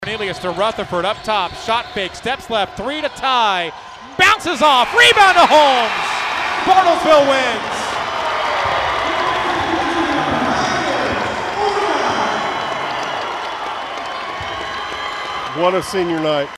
Here was the final call as heard on KWON.
Final Call Bruin W over Stillwater 2-14.mp3